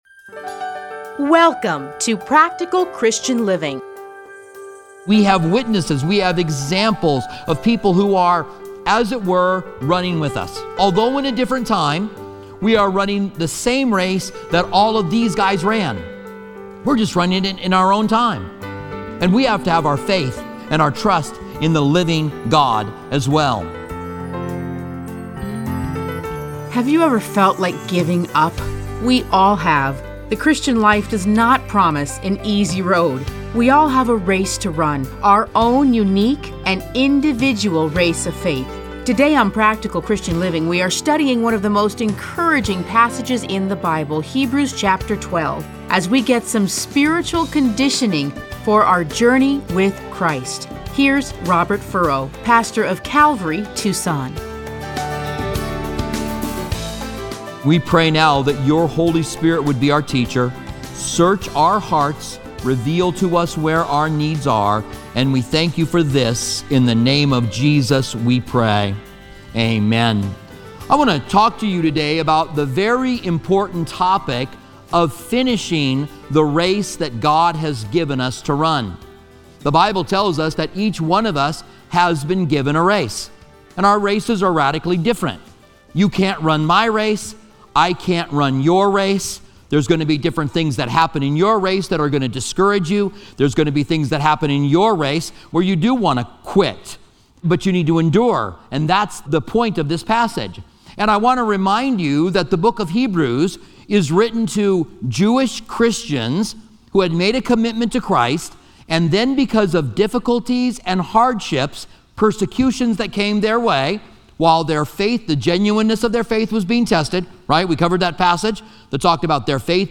Listen to a teaching from Hebrews 12:1-3.